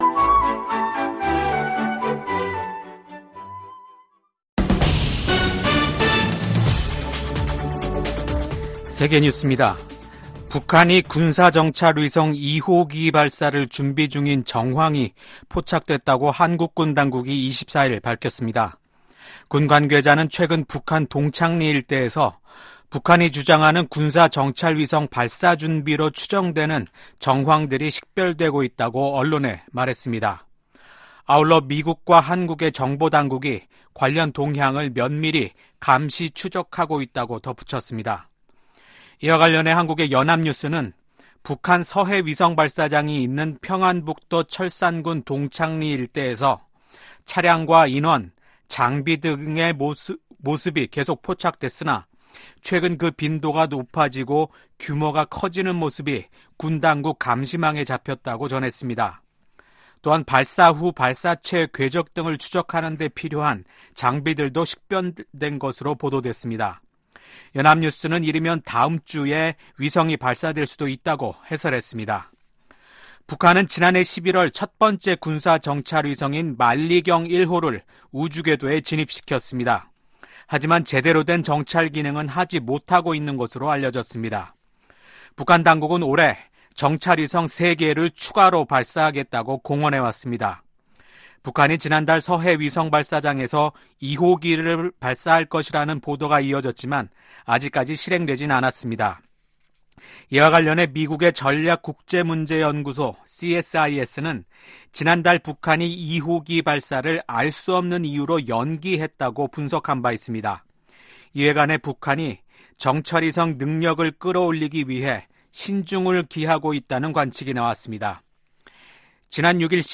VOA 한국어 아침 뉴스 프로그램 '워싱턴 뉴스 광장' 2024년 5월 25일 방송입니다. 미 국무부는 미국과 그 동맹들이 역내 긴장을 고조시킨다는 러시아의 주장을 일축하고, 긴장 고조의 원인은 북한에 있다고 반박했습니다. 2025회계연도 미국 국방수권법안이 하원 군사위원회를 통과했습니다.